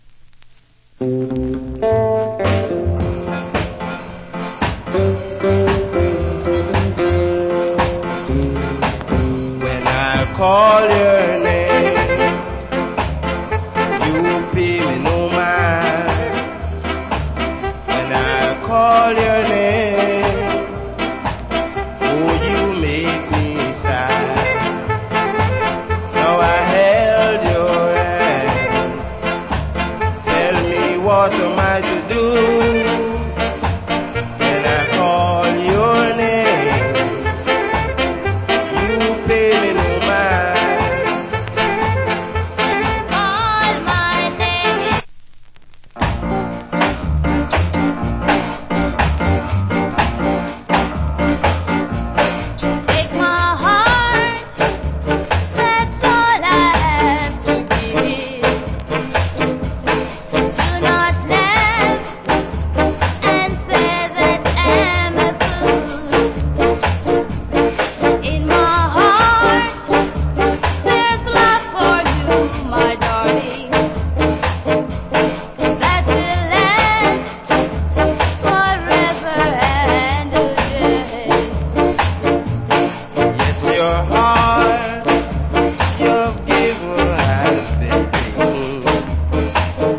Great Duet Ska Vocal.